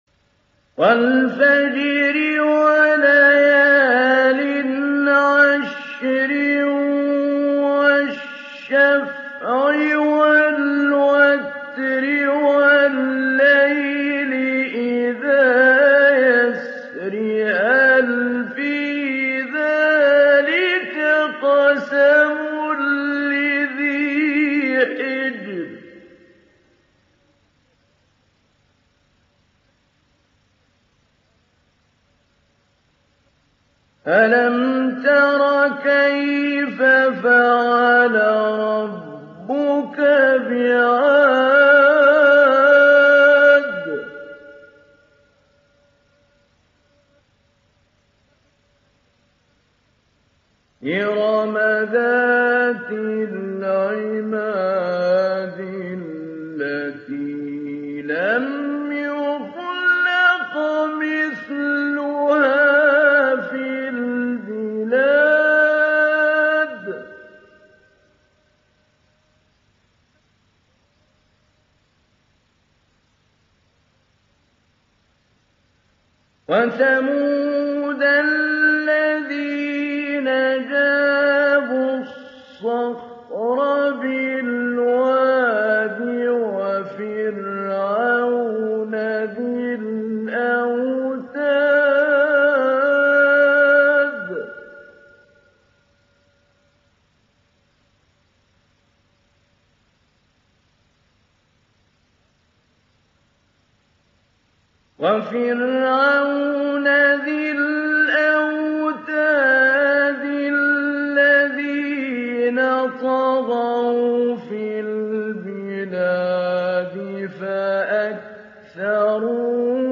تحميل سورة الفجر mp3 بصوت محمود علي البنا مجود برواية حفص عن عاصم, تحميل استماع القرآن الكريم على الجوال mp3 كاملا بروابط مباشرة وسريعة
تحميل سورة الفجر محمود علي البنا مجود